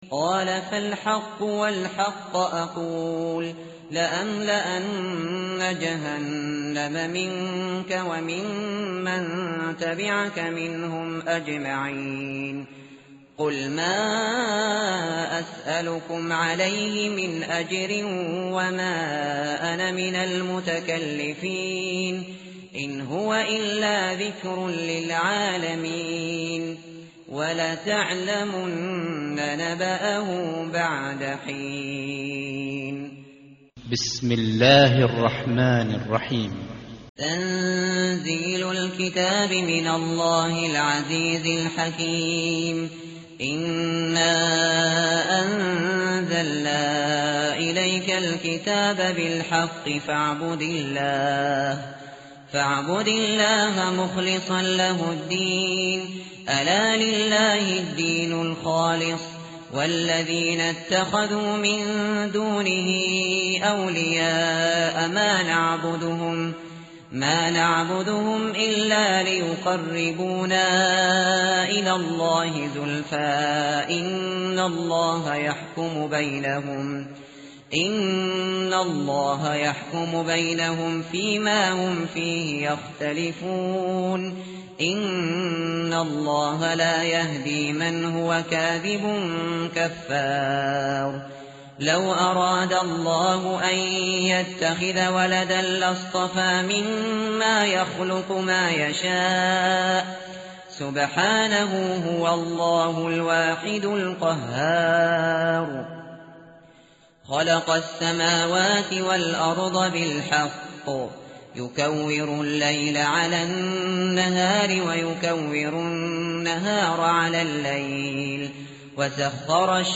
tartil_shateri_page_458.mp3